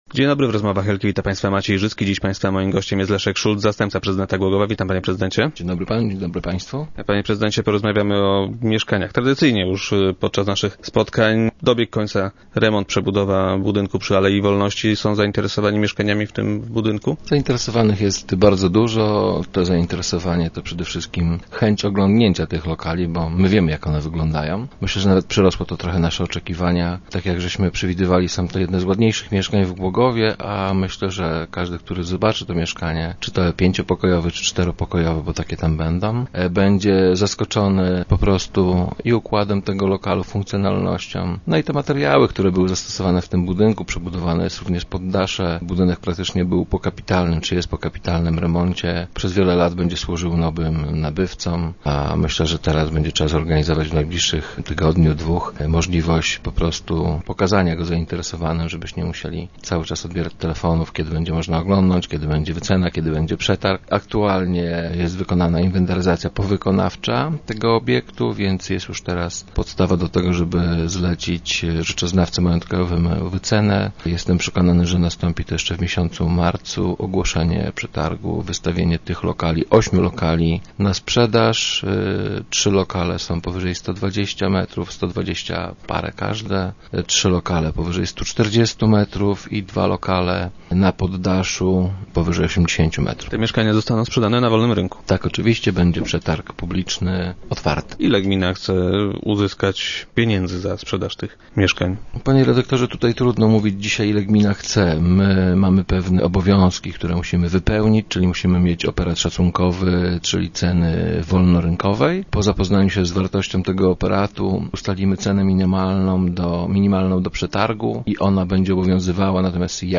Jak powiedział nam Leszek Szulc, zastępca prezydenta Głogowa  i dzisiejszy gość Rozmów Elki, zainteresowanie nimi jest bardzo duże.